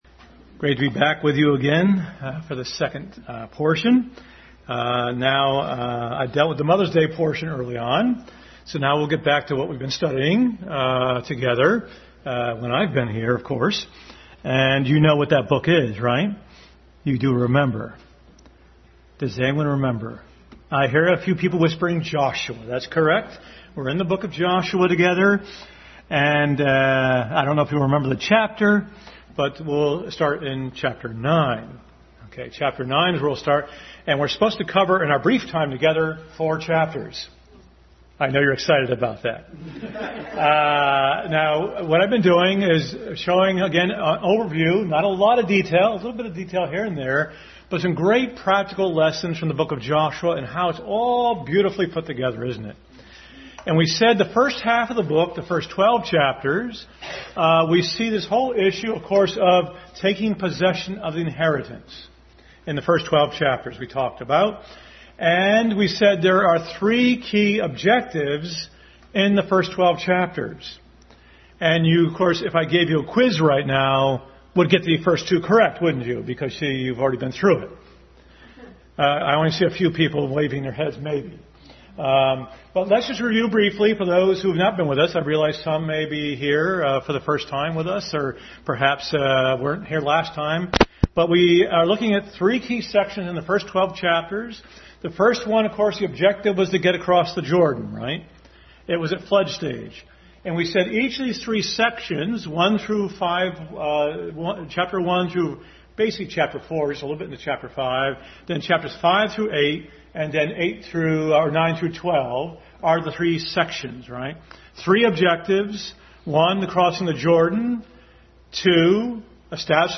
Family Bible Hour Message.